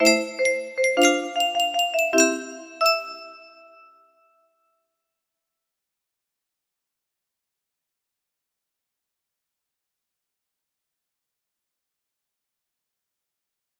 M11-M12 music box melody